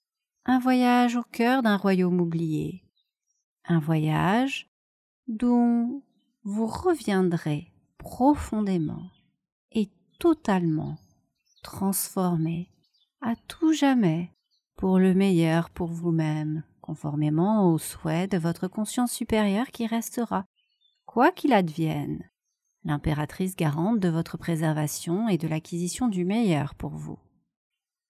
Cet audio est doux et ressourçant, vous permettant d’aller rencontrer avec fluidité des parts de vous qui sont demeurent oubliées ou cachée, dans l’ombre de la non-conscience.
Cela se fait très progressivement, en douceur et à notre rythme.